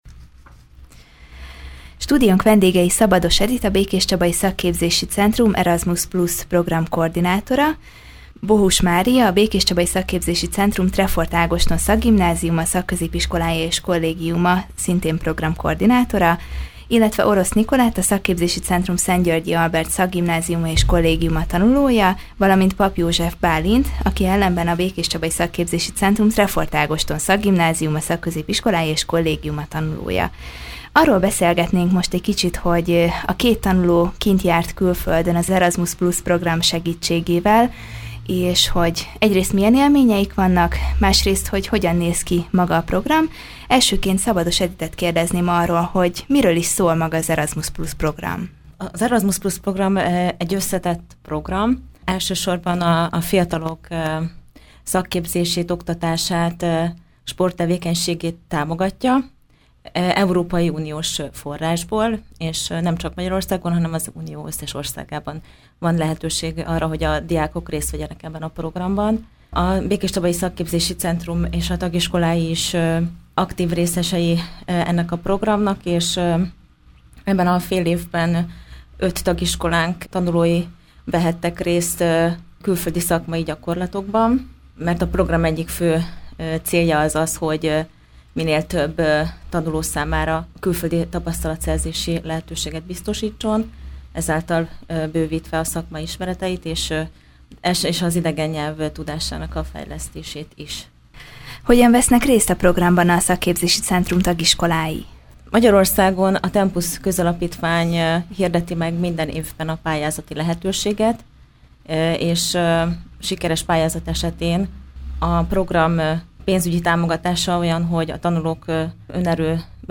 A Békéscsabai Szakképzési Centrum több diákja is részt vesz az Erasmus+ programban. Ezzel kapcsolatban voltak stúdiónk vendégei.